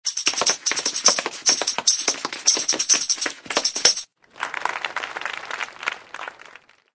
AA_heal_happydance.ogg